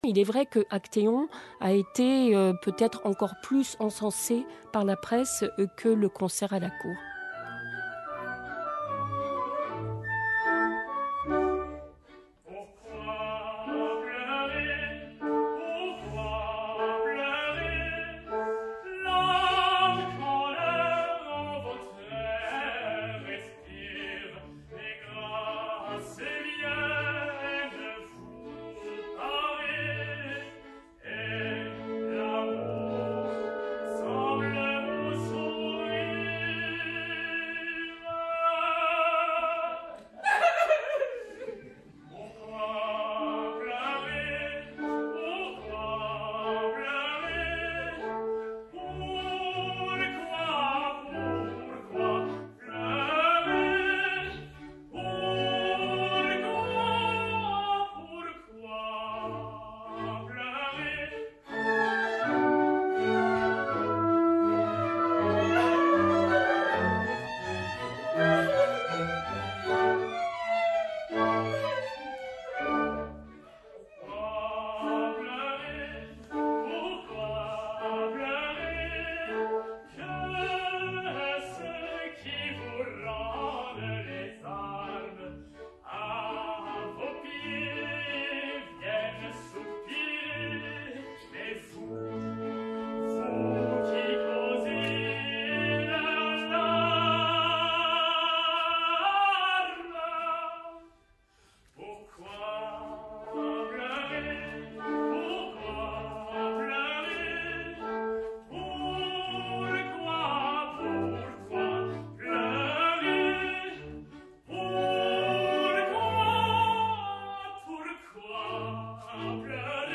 • Le 20 avril 2025, une émission de France Musique est dédiée au projet Auber & Scribe.